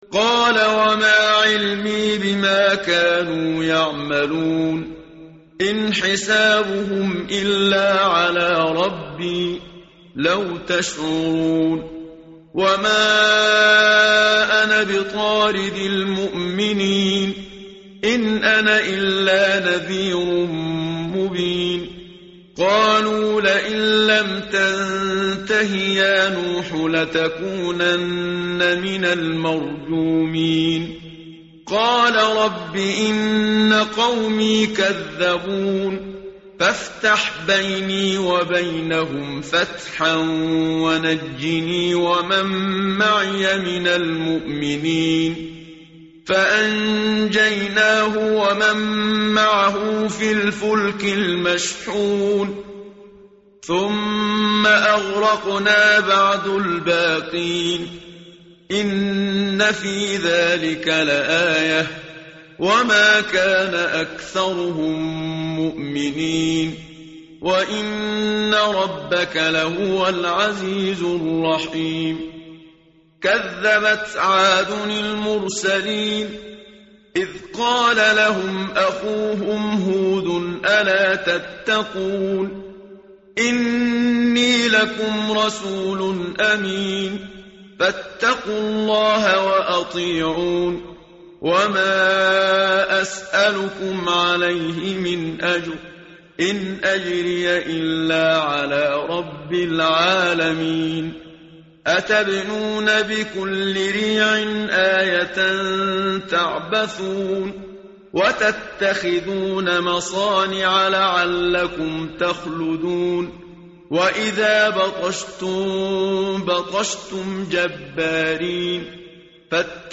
متن قرآن همراه باتلاوت قرآن و ترجمه
tartil_menshavi_page_372.mp3